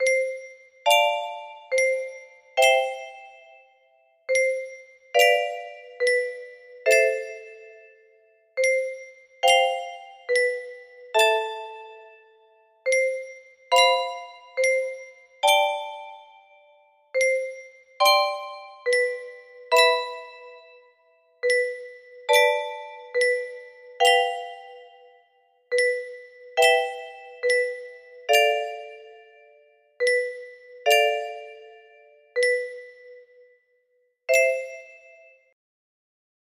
Spring Rain in the Distance music box melody